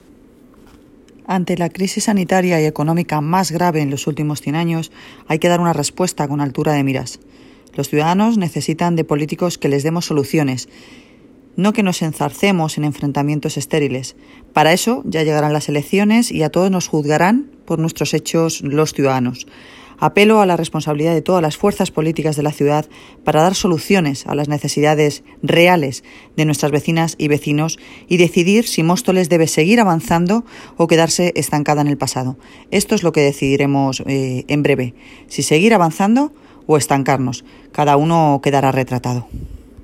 Audio - Noelia Posse (Alcaldesa de Móstoles)